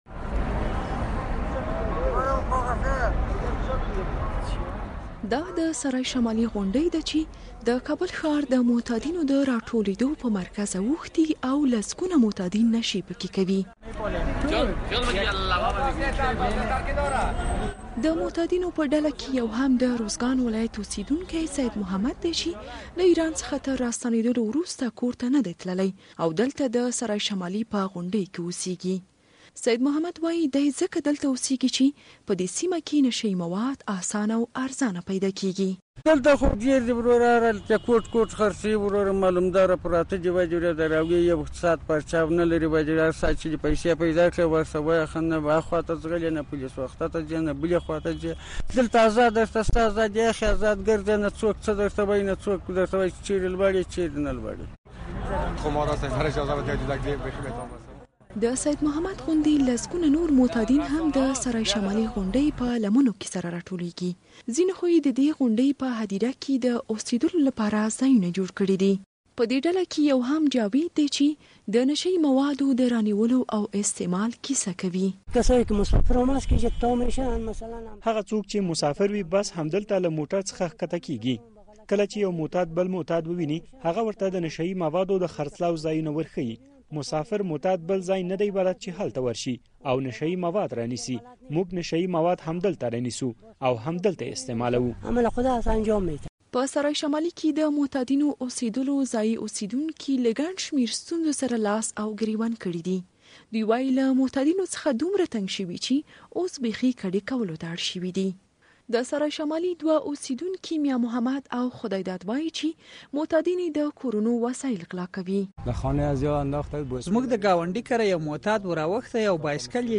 د نشه يي مواد د خرڅلاو په اړه فیچر